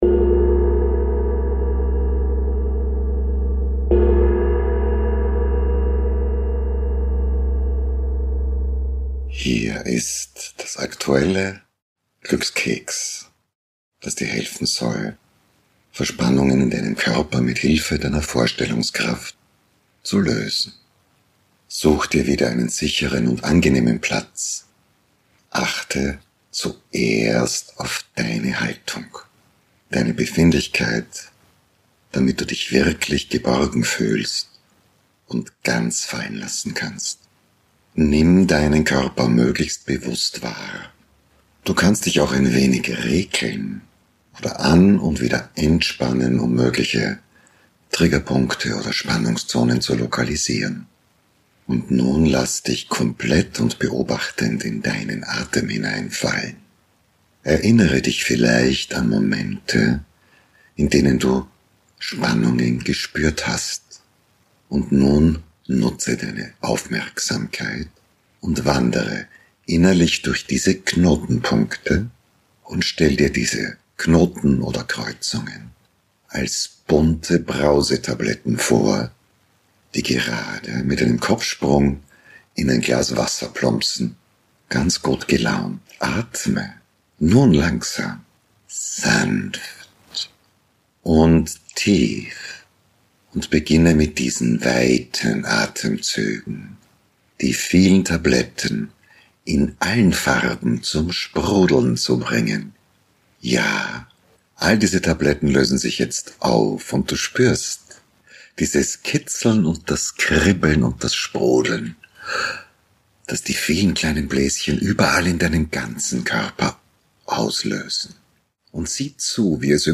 Meditation zur Lösung körperlicher Verspannungen – sanft, entspannend und alltagstauglich.